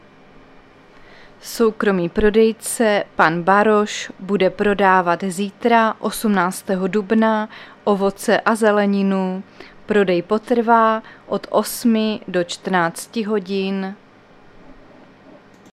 Záznam hlášení místního rozhlasu 17.4.2024
Zařazení: Rozhlas